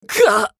男性
厨二病ボイス～戦闘ボイス～
【ダメージ（強）1】